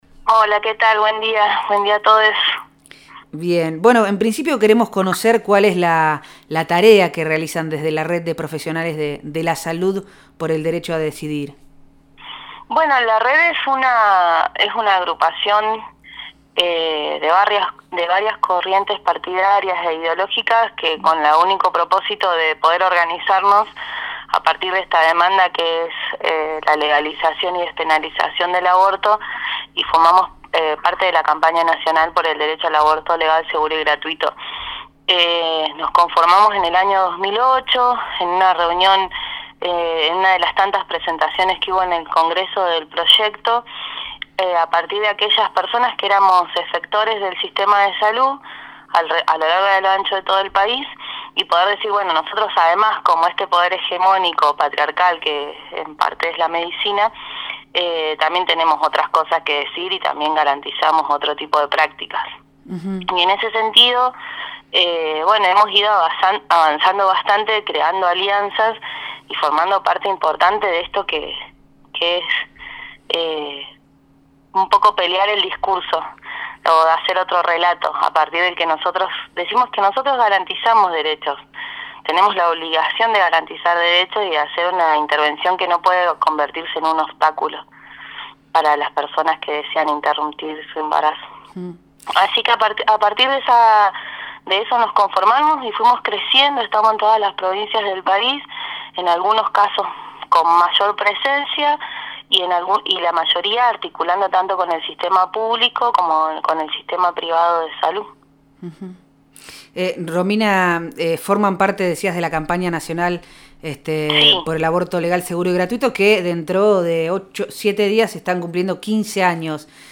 Proyecto Erre Radio , de lunes a viernes de 9 a 11 am por FM Vox Populi, 100.1; y por FM Del Barrio, 98.1